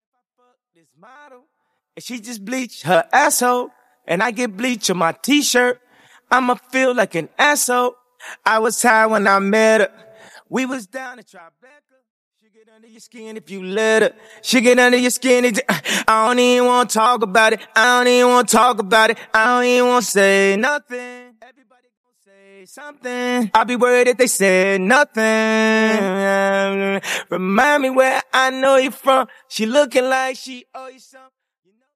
Note : backing and leading vocals!